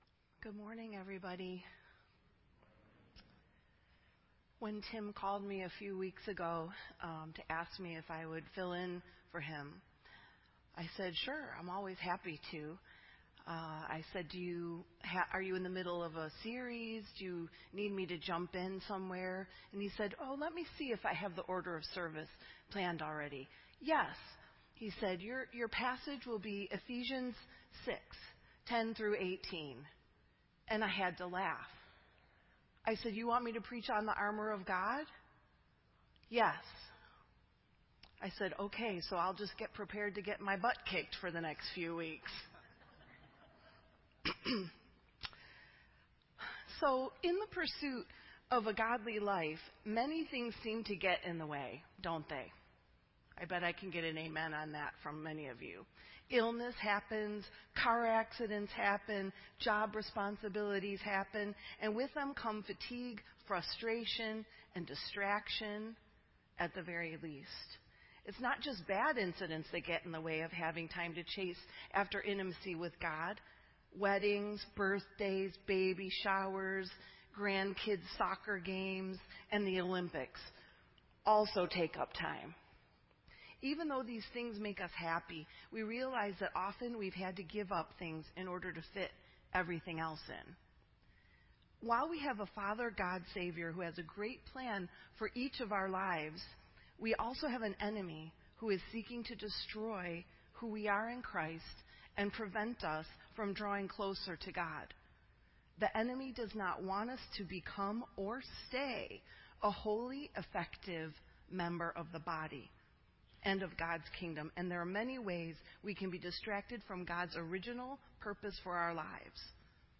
This entry was posted in Sermon Audio on September 6